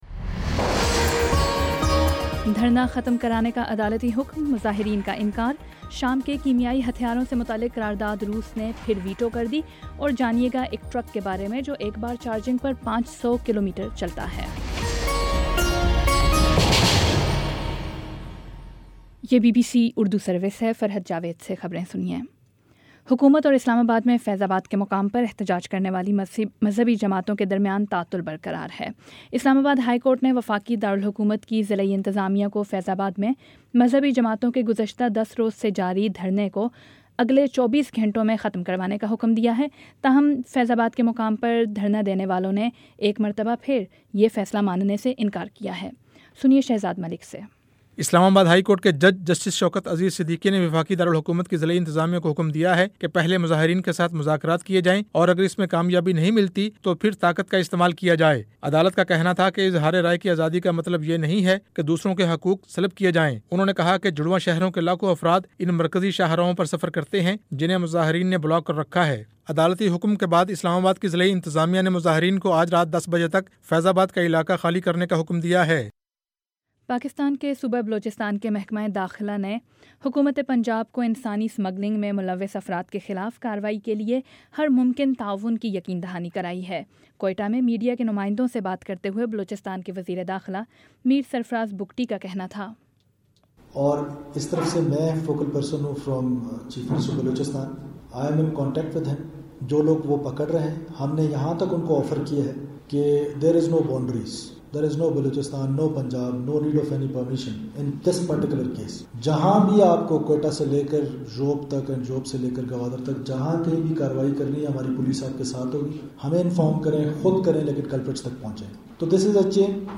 نومبر 17 : شام چھ بجے کا نیوز بُلیٹن